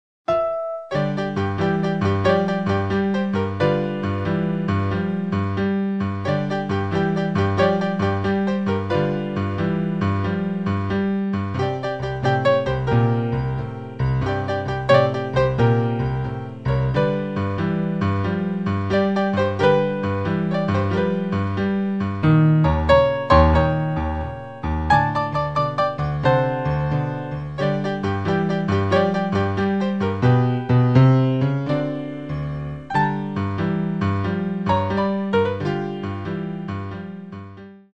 Style: Boogie Woogie Piano
Ein einfacher Boogie Woogie im Barrelhouse Piano Stil.